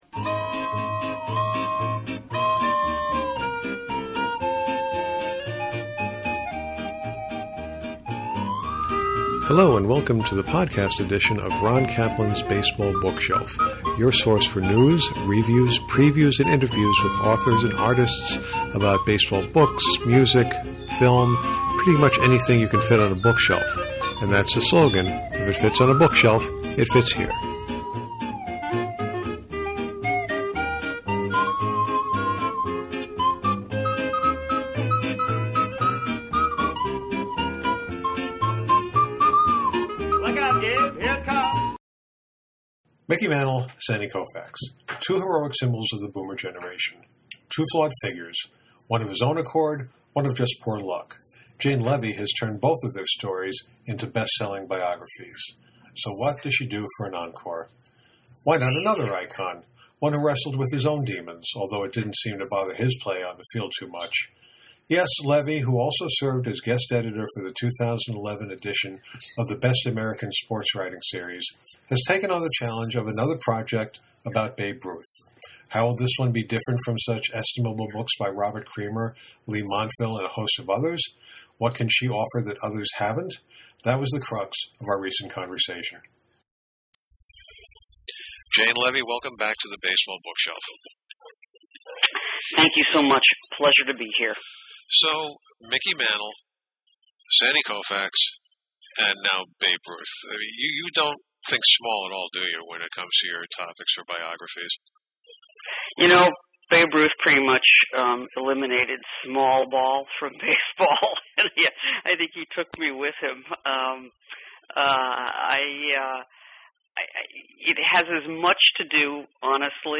That was the crux of our recent conversation.